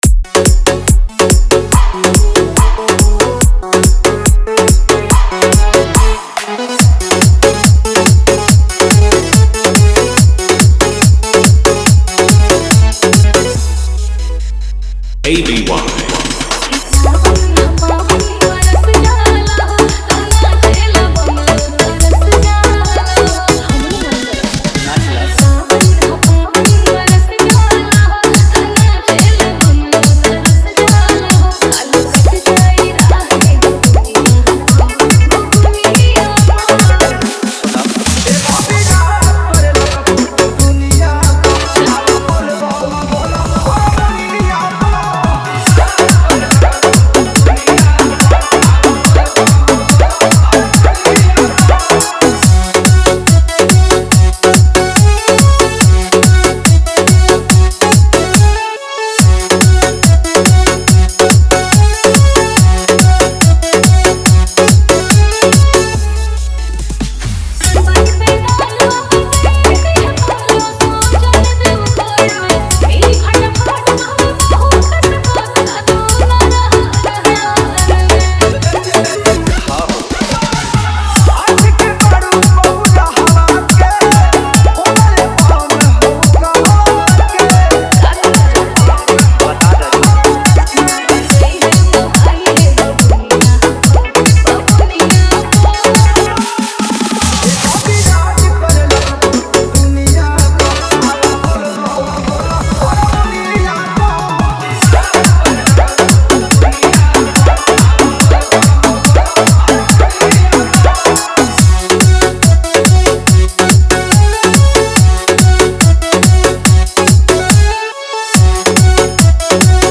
Bolbam 2025 Dj Songs Report This Song Play Pause Vol + Vol -